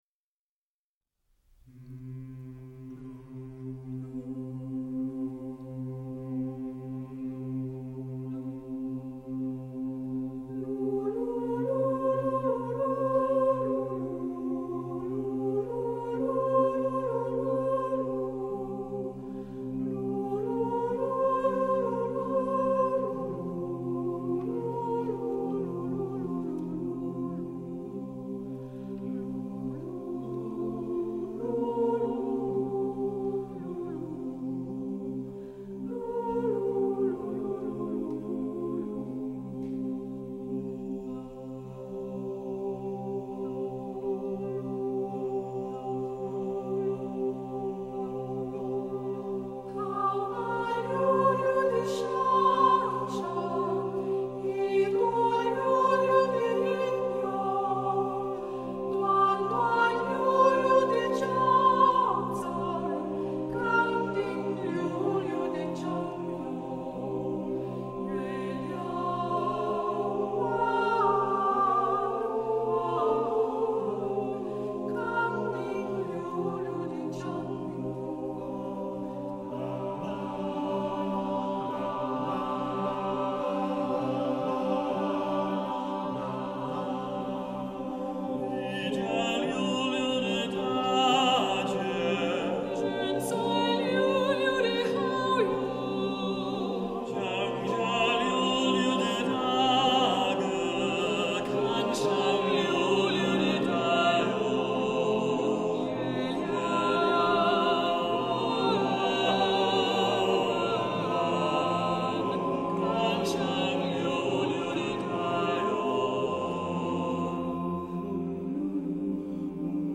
Accompaniment:      A Cappella, Rehearsal Piano
Music Category:      World